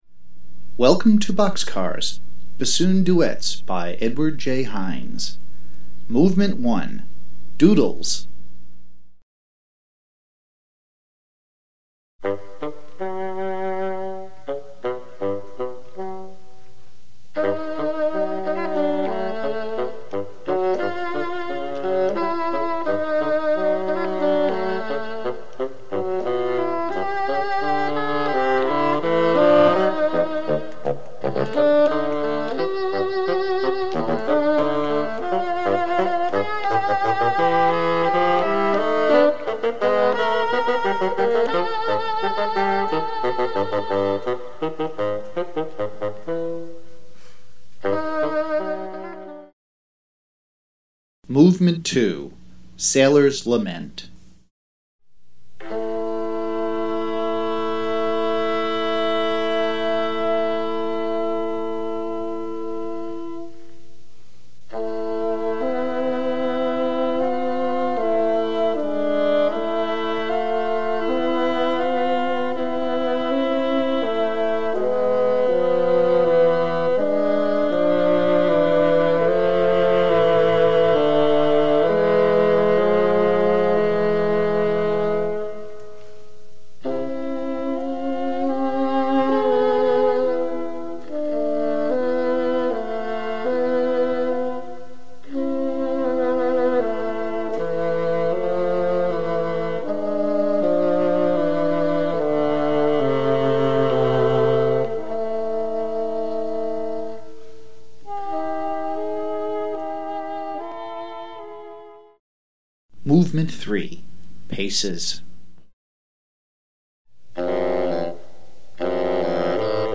Mp3 Audio File: four movements • 9 minutes duration
Bassoon Quartet